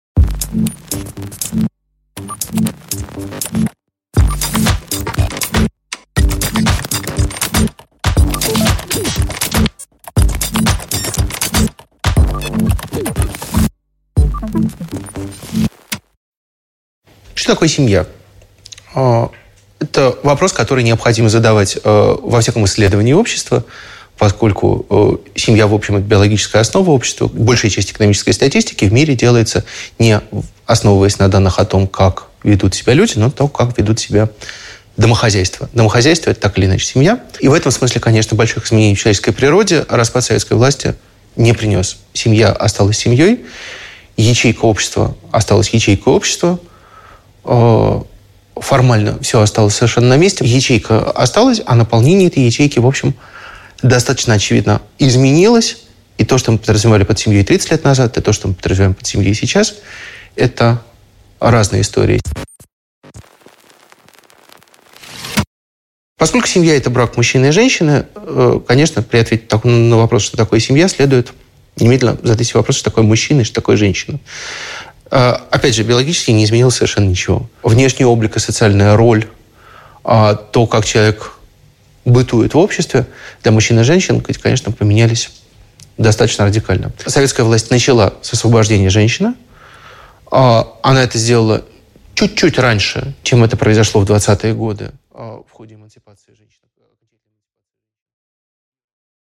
Аудиокнига Содержимое ячейки: неопределенное будущее семьи | Библиотека аудиокниг